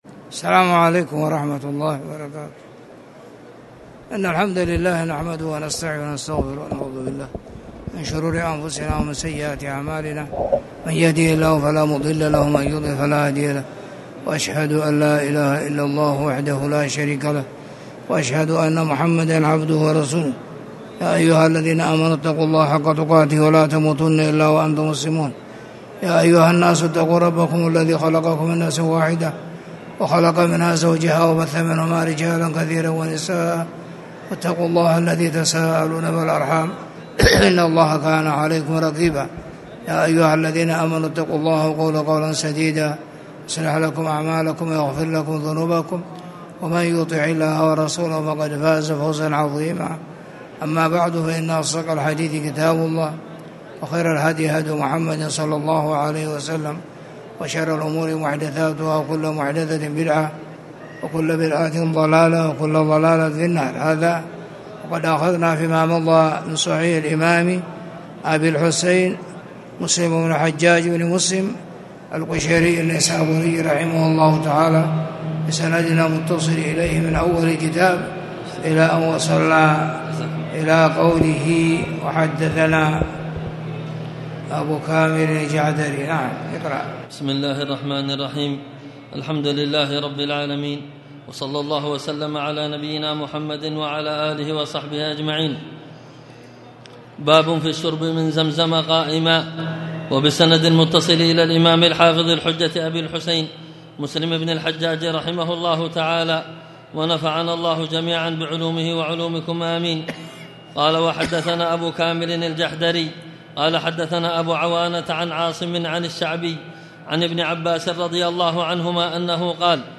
تاريخ النشر ٢٢ شوال ١٤٣٨ هـ المكان: المسجد الحرام الشيخ